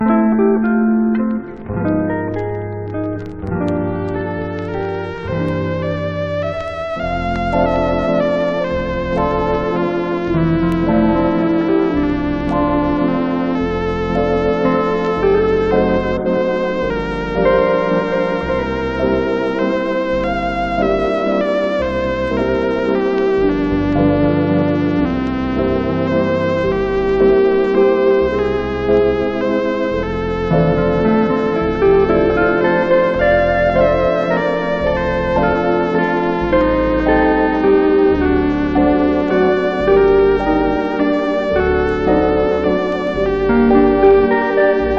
小編成でゆったりとリラックスしつつ、巧みな演奏が嬉しい1枚です。
Jazz, Easy Listening　USA　12inchレコード　33rpm　Mono